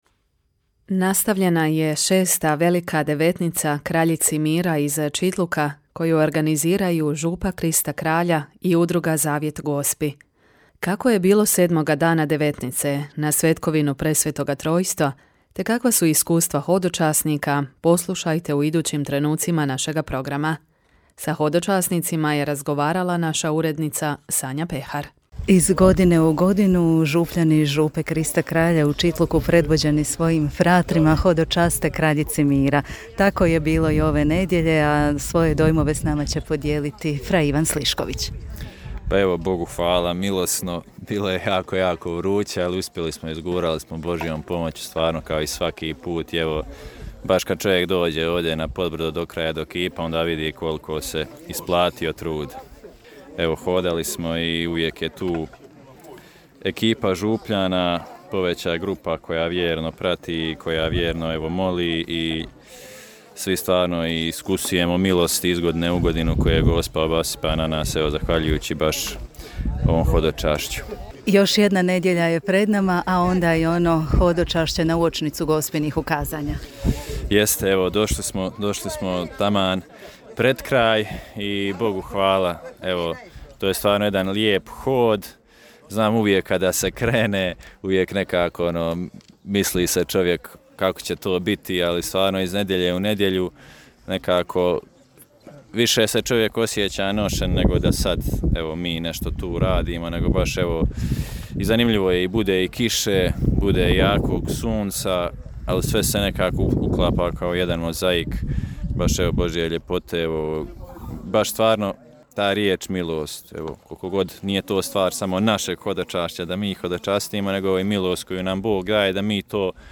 Nastavljena je  6. velika devetnica Kraljici Mira iz Čitluka u organizaciji župe Krista Kralja i udruge Zavjet Gospi. Kako je bilo sedmog dana devetnice, na svetkovinu Presvetog Trojstva 15. lipnja te kakva su iskustva hodočasnika donijeli smo u međugorskim minutama.